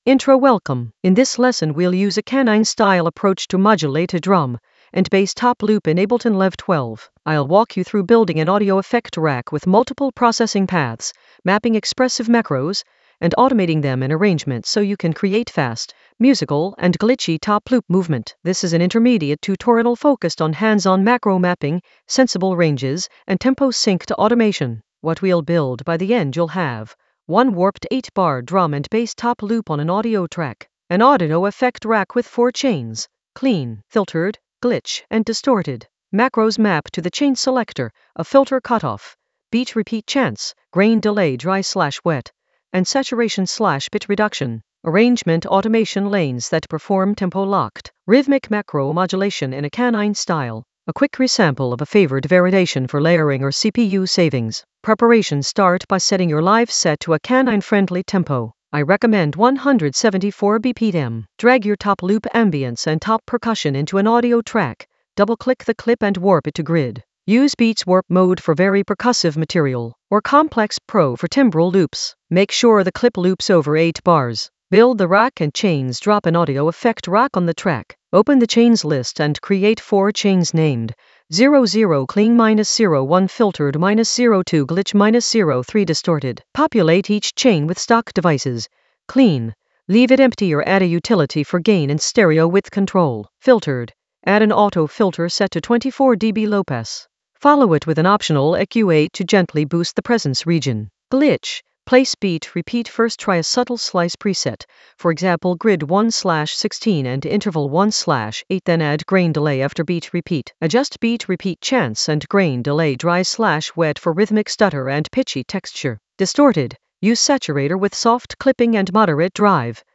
An AI-generated intermediate Ableton lesson focused on Kanine approach: modulate a top loop in Ableton Live 12 using macro controls creatively in the Automation area of drum and bass production.
Narrated lesson audio
The voice track includes the tutorial plus extra teacher commentary.